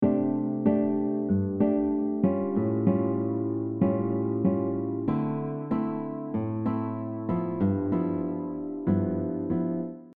This mellow and beautiful style uses syncopated rhythms with a fingerpicking style of playing.
For the chords that start on the 6th string, we’ll repeat the bass note instead of alternating with another note.
Bossa nova rhythms with a chord progression
Bossa-Nova-pattern-6-.mp3